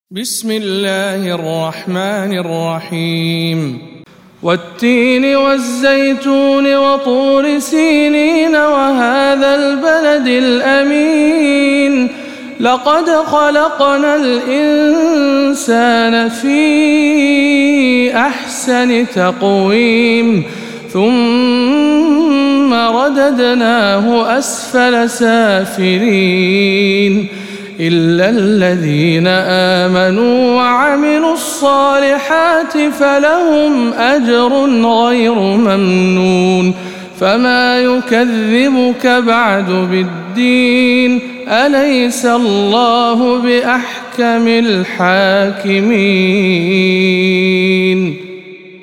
سورة التين - رواية أبو الحارث